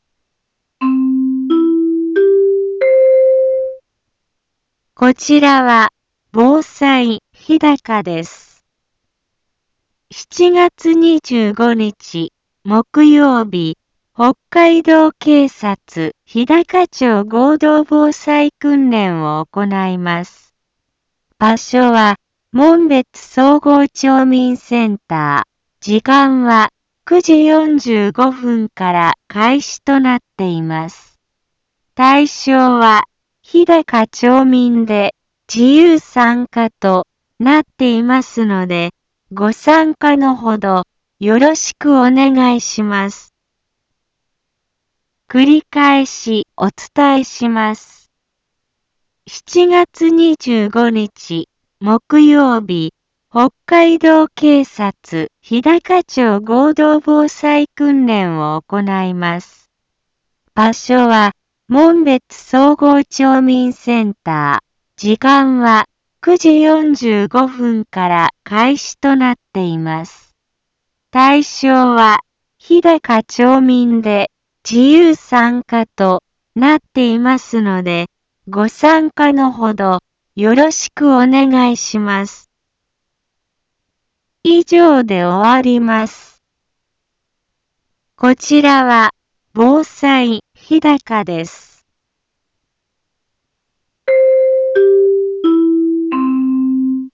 Back Home 一般放送情報 音声放送 再生 一般放送情報 登録日時：2019-07-22 10:03:27 タイトル：「７月25防災訓練について」 インフォメーション： こちらは、防災日高です。